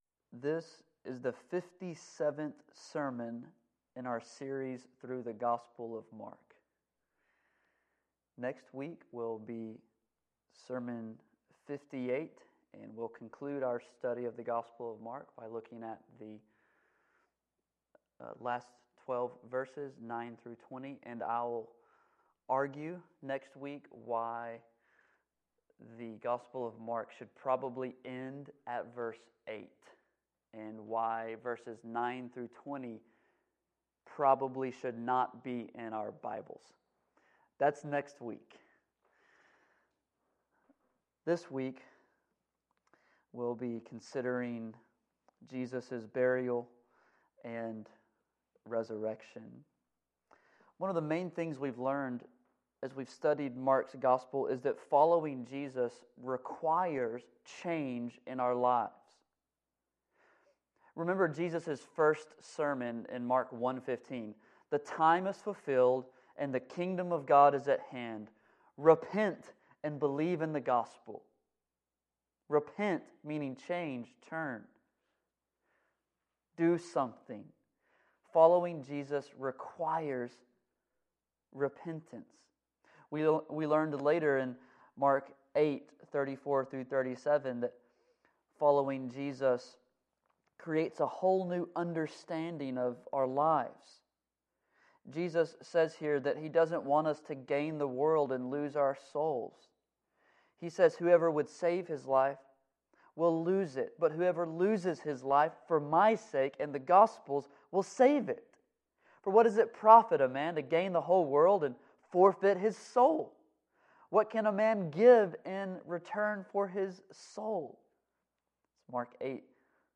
Our Cars Need Gas This is the fifty-seventh sermon in our series through the Gospel of Mark.